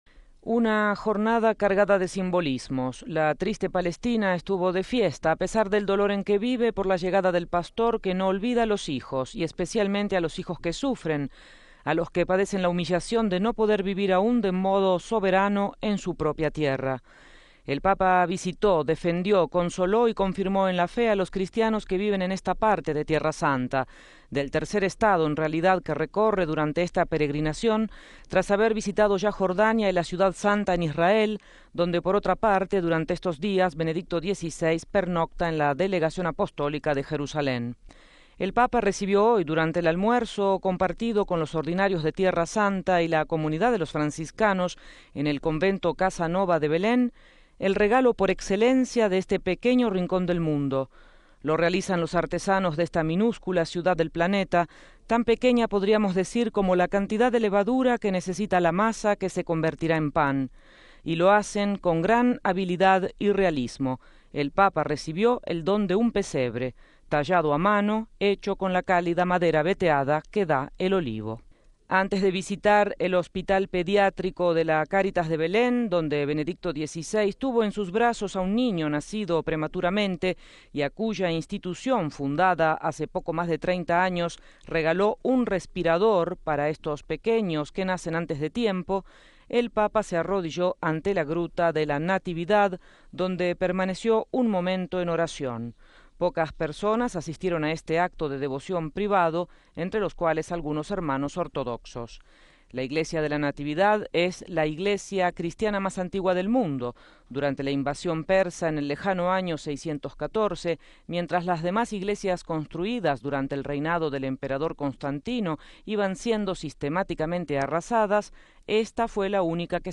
Crónica desde Jerusalén RealAudio